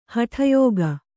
♪ haṭha yōga